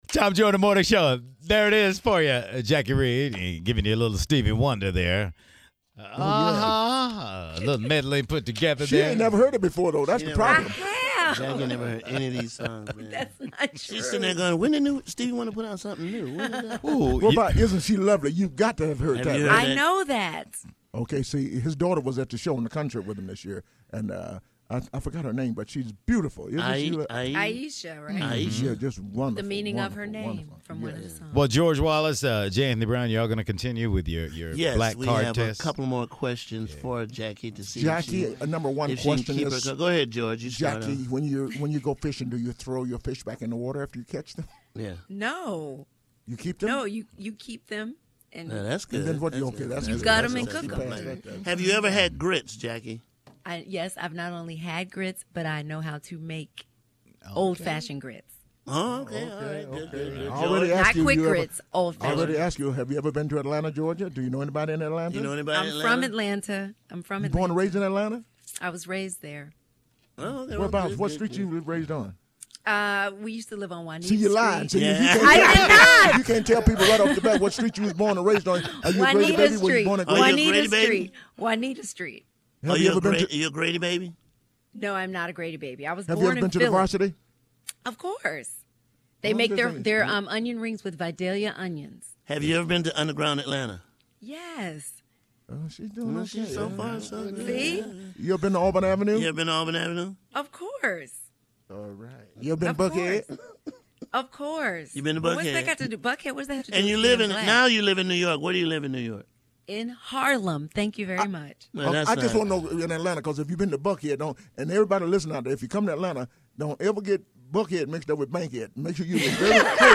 Comedian/actor George Wallace talks to the Tom Joyner Morning Show about his plans for New Year’s Eve, but not before he gives Jacque Reid a test on her “blackness”.